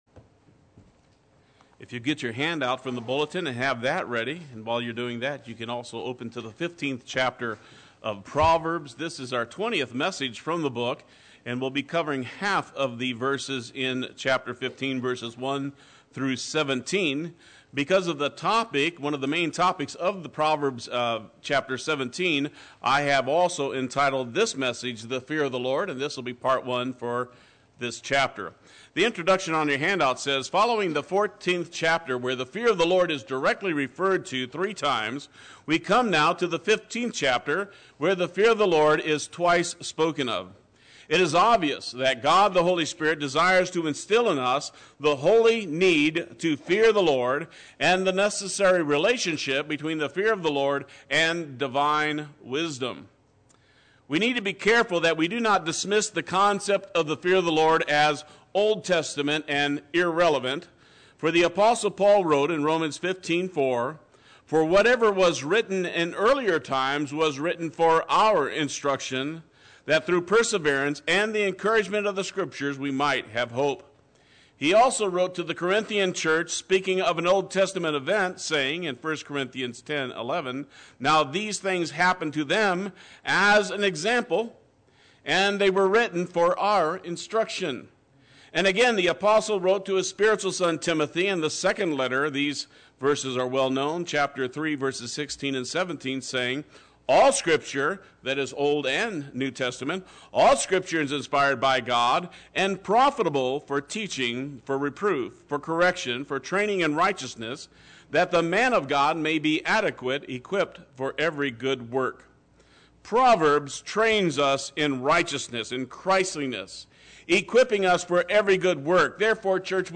Play Sermon Get HCF Teaching Automatically.
The Fear of the Lord Sunday Worship